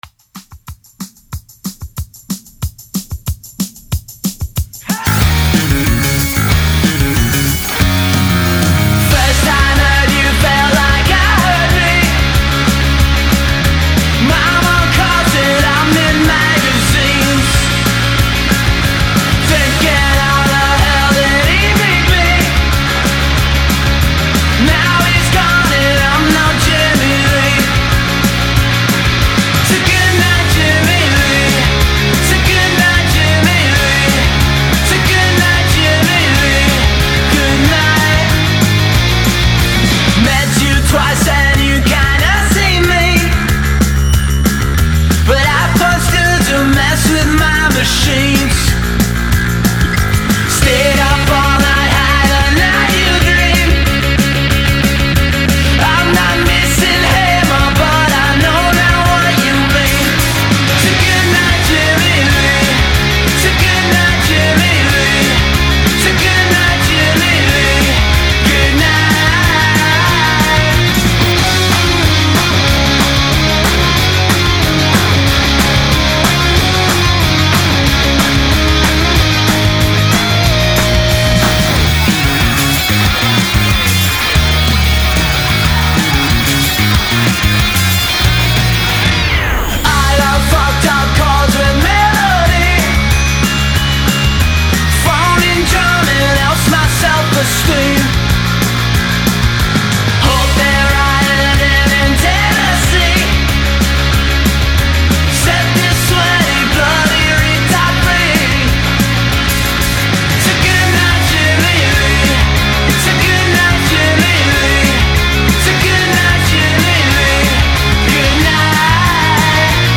Not changing my life…but its punchy.